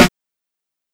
Snare (Simple As...).wav